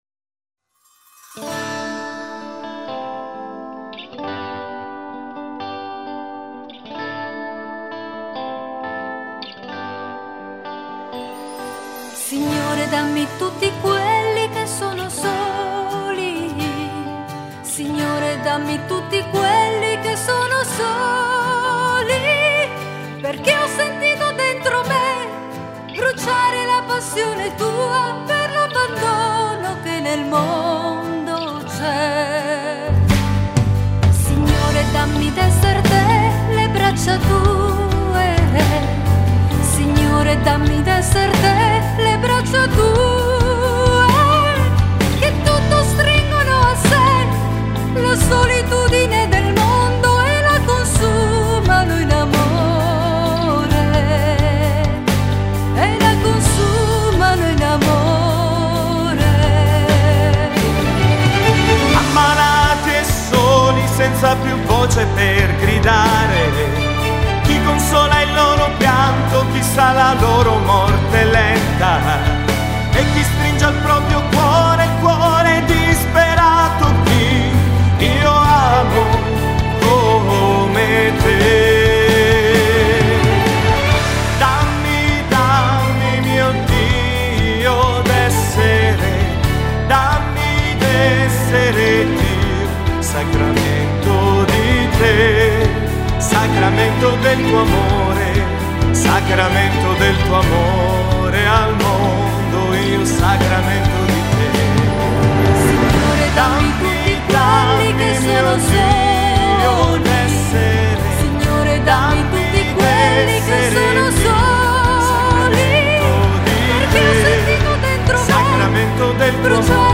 concerto che spesso faccio dal vivo